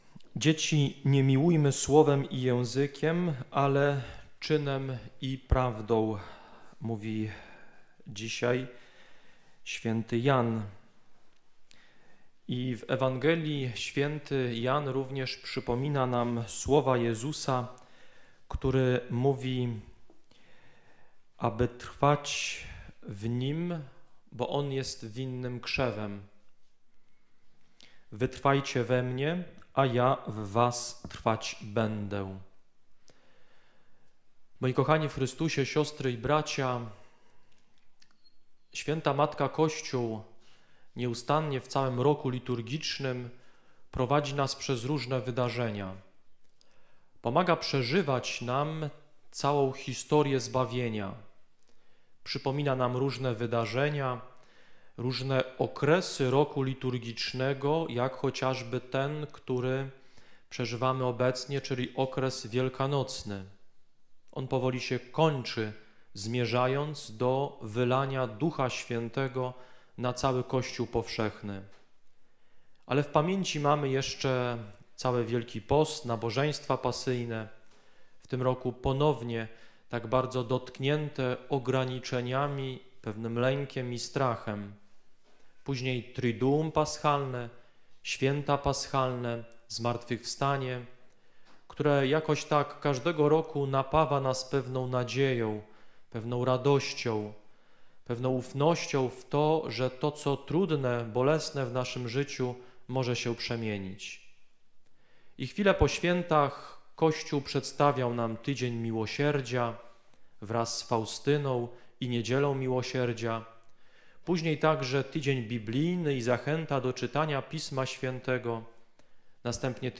5 Niedziela Wielkanocna – homilia